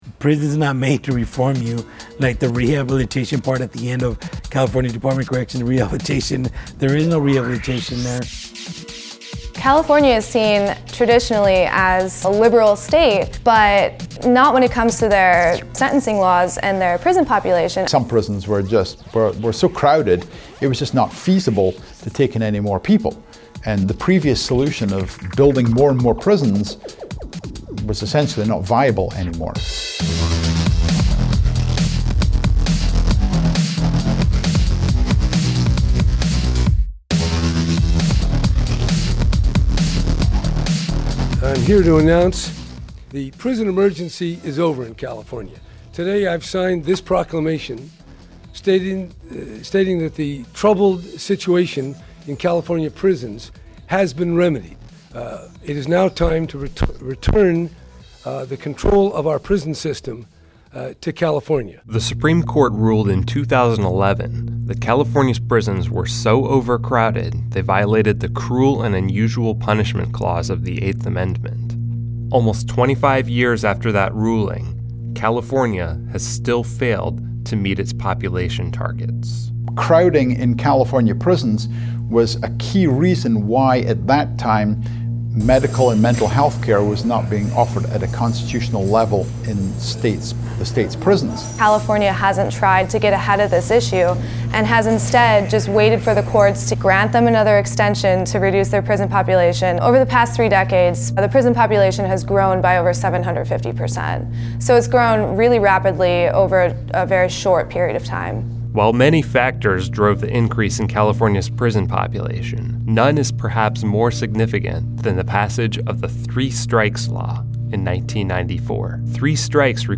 Watch the video above for a deeper dive into the politics of California's prisons, featuring interviews with state prison officials, local sheriffs, and former inmates.